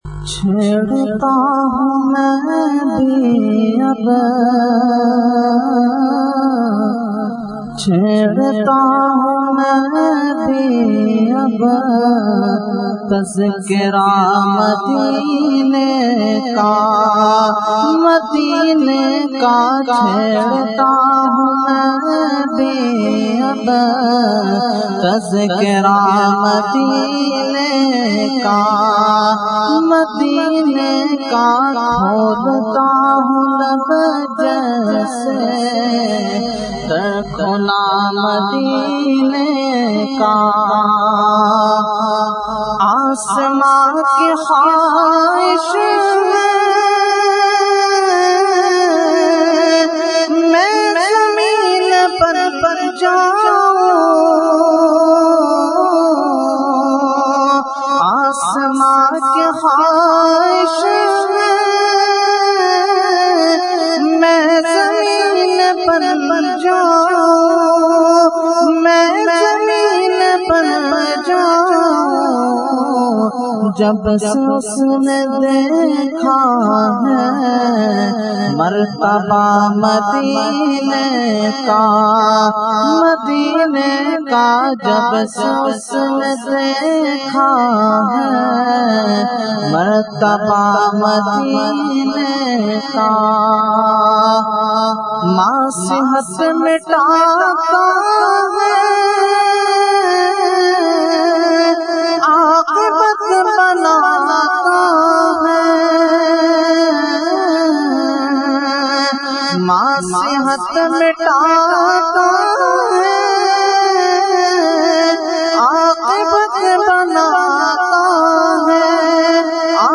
Category : Naat | Language : UrduEvent : Salana Fatiha Ashraful Mashaikh 2013